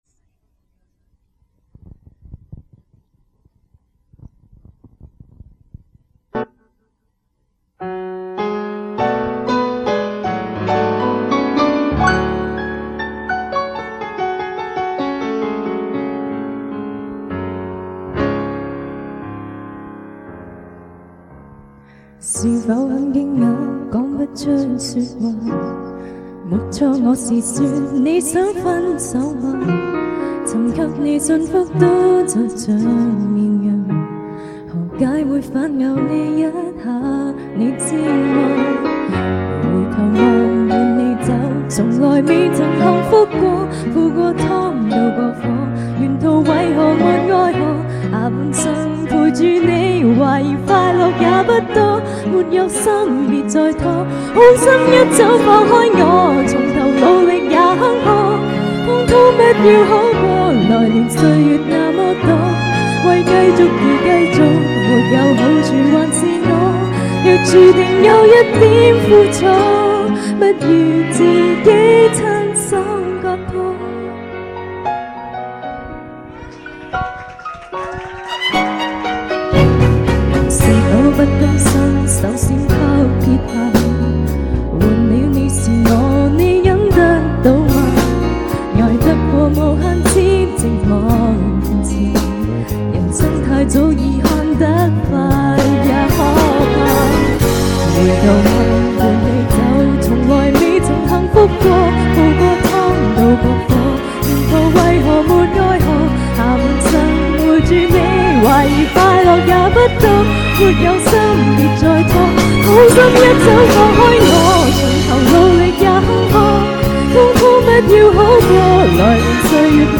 On December 4th 2004, members of various classes gathered at Emerald City Chinese Restaurant to find out who is the King/Queen of Karaoke.